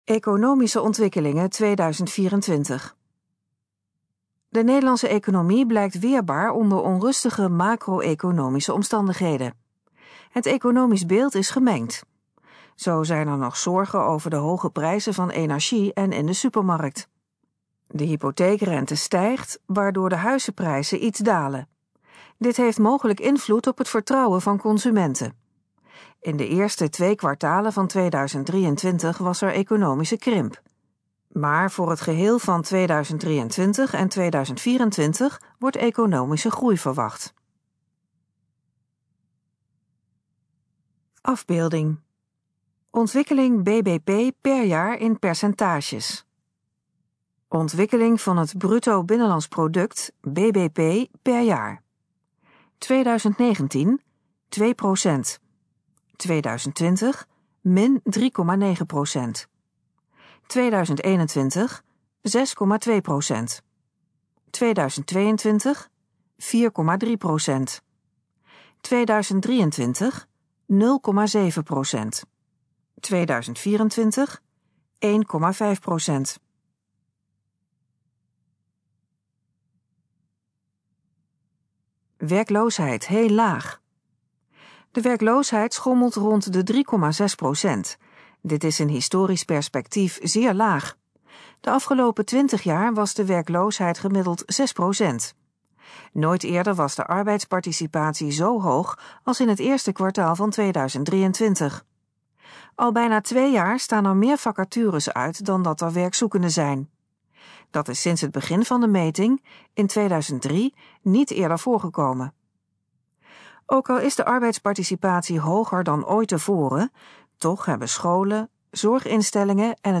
Gesproken versie van Economische ontwikkelingen 2024
In het volgende geluidsfragment hoort u informatie over de economische ontwikkelingen in 2024. Het fragment is de gesproken versie van de informatie op de pagina Economische ontwikkelingen 2024.